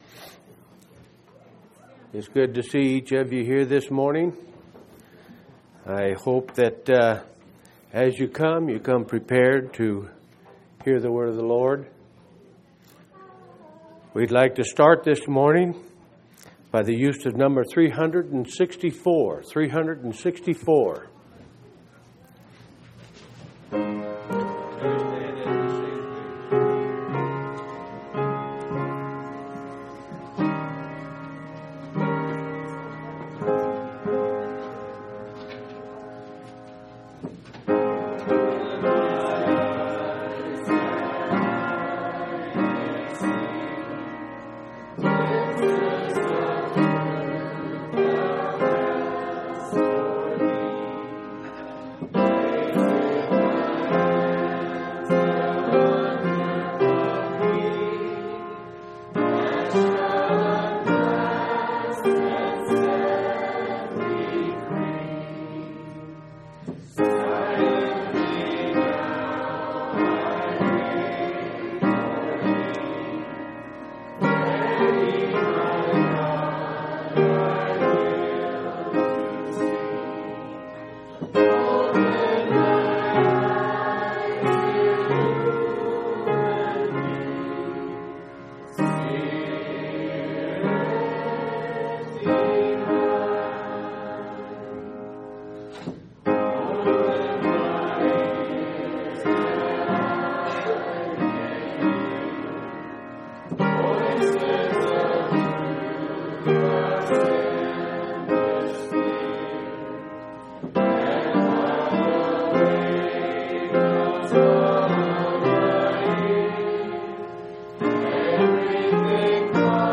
3/28/2004 Location: Phoenix Local Event